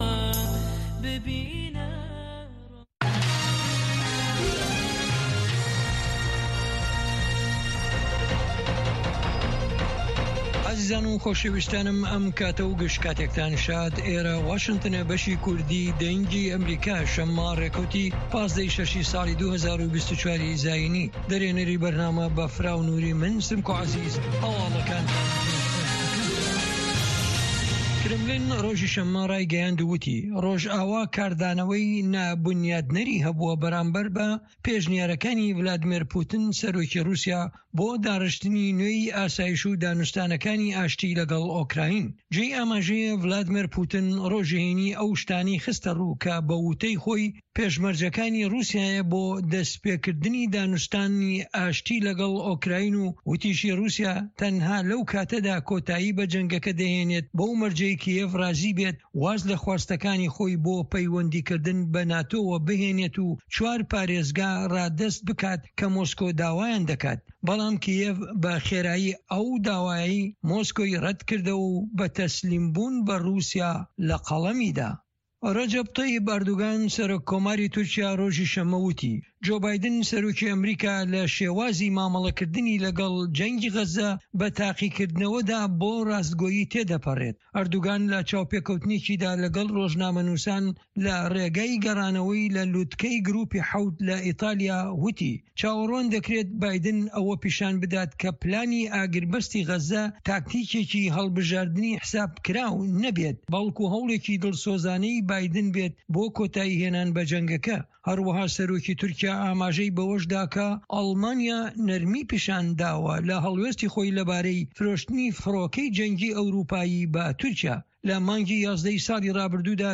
Nûçeyên 1’ê paşnîvro